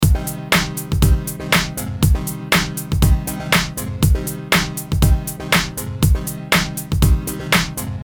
Treated with traditional side chaining the synth loop is compressed continually
The synth line is side chained using the whole loop as a trigger